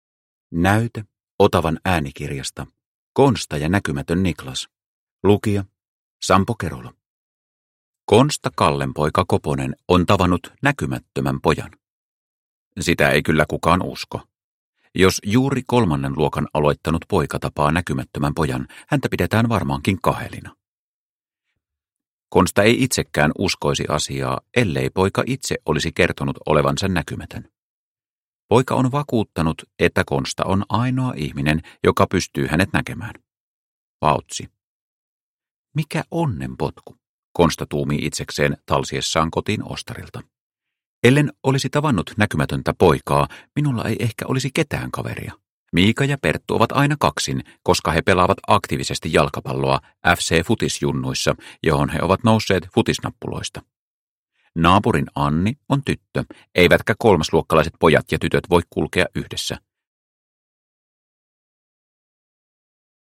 Konsta ja näkymätön Niklas – Ljudbok – Laddas ner